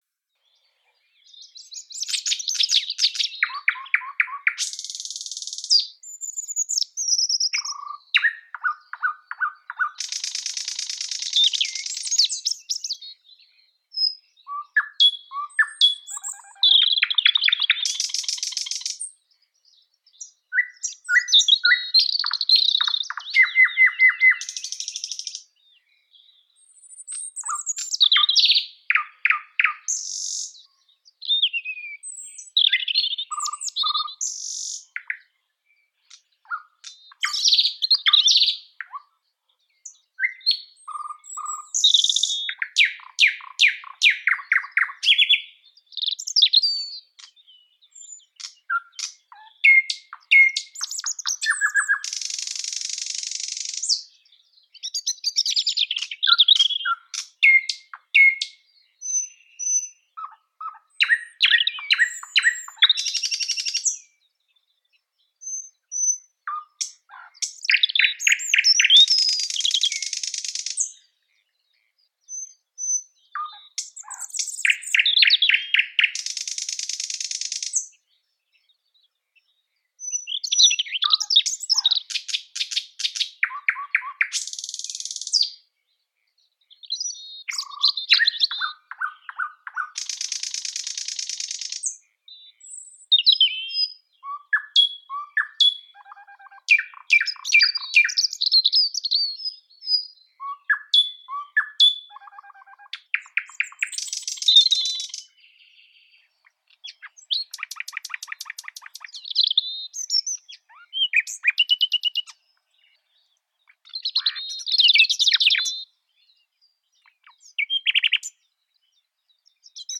соловей_и_зарянка
soloveq_i_zaryanka.mp3